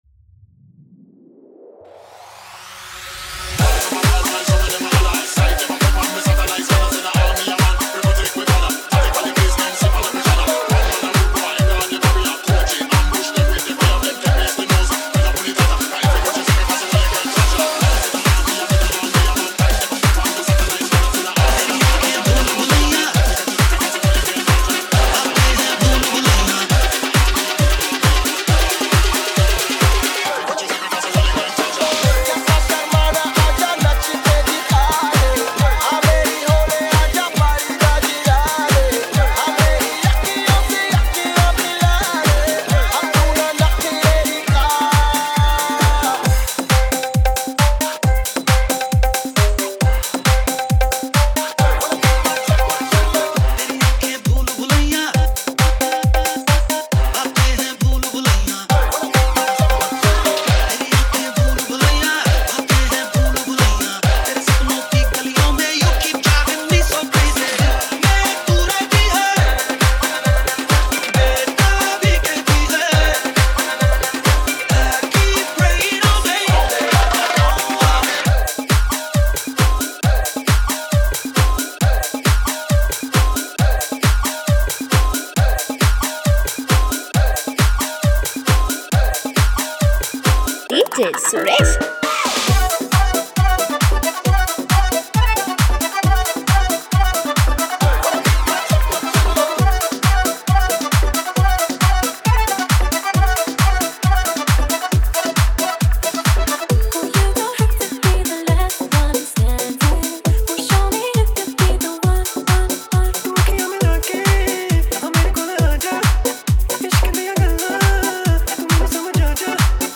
Sinhala DJ English DJ English sinhala DJ Hindi DJ